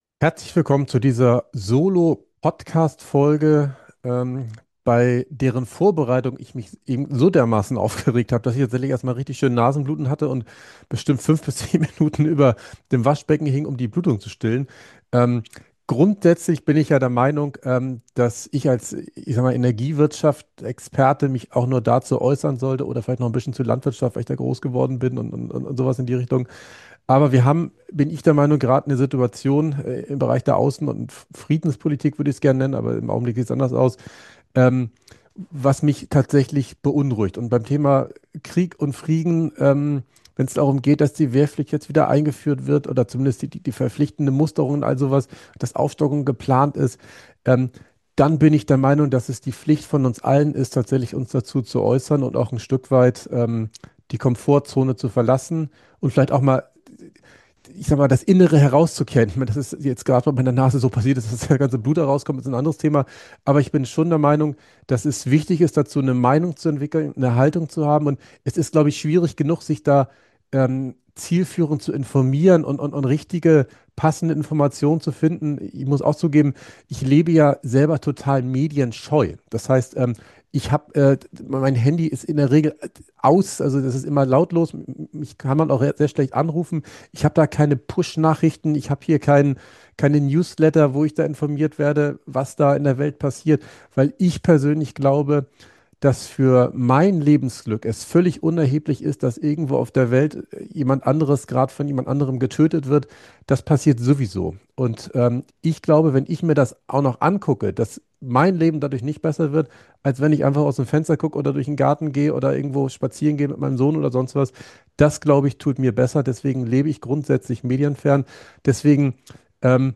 Diese Solo-Folge ist emotionaler als viele andere.